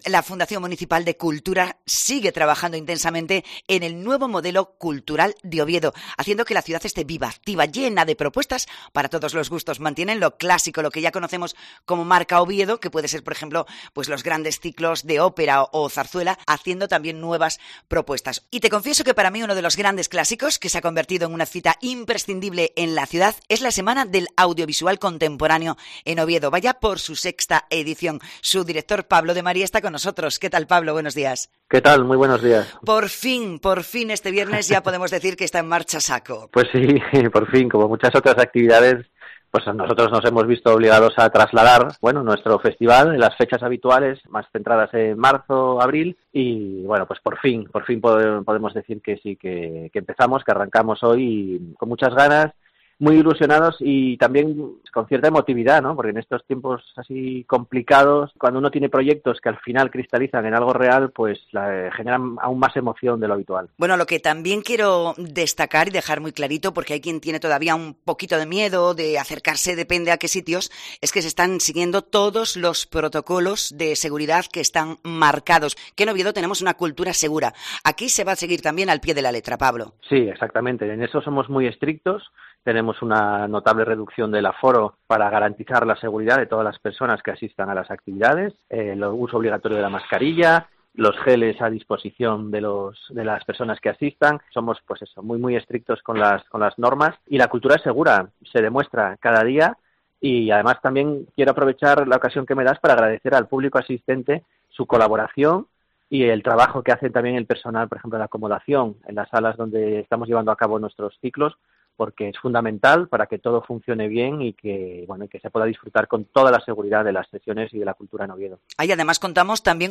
nos habla en Mediodía Cope Asturias de las actividades más destacas de esta cita cultural.